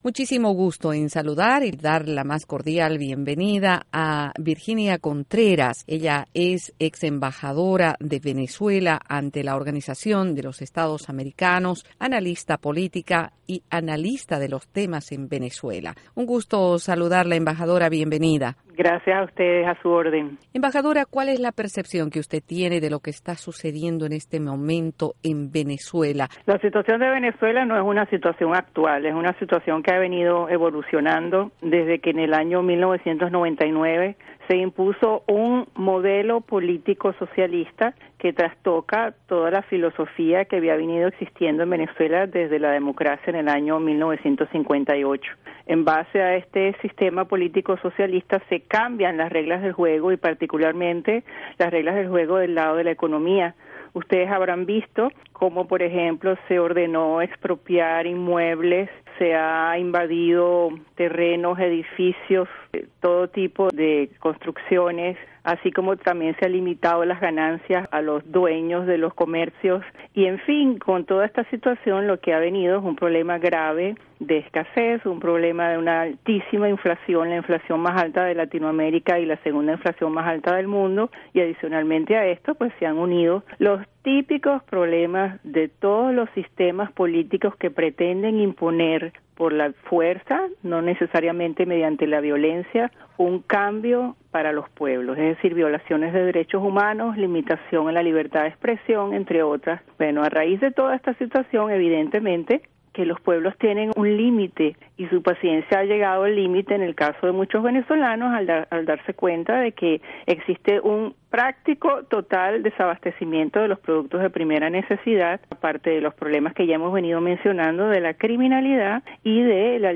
Entrevista embajadora Virginia Contreras